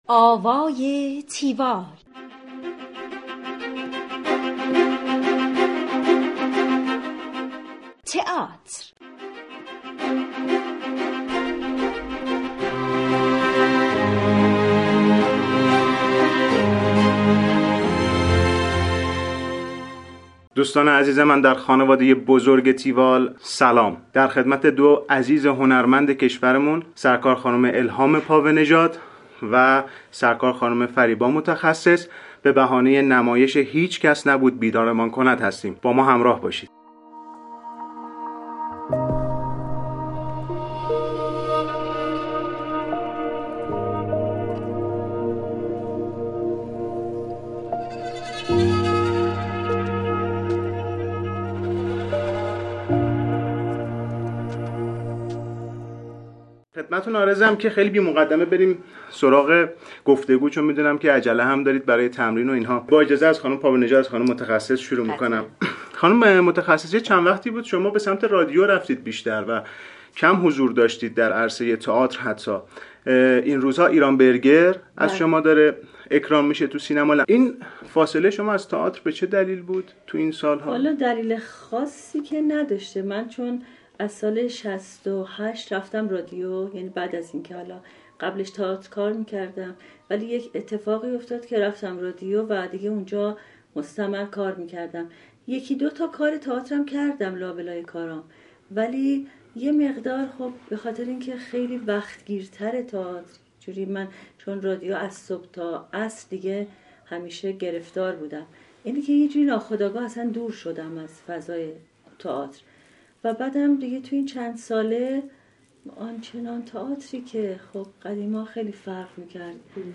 گفتگوی تیوال با فریبا متخصص و الهام پاوه نژاد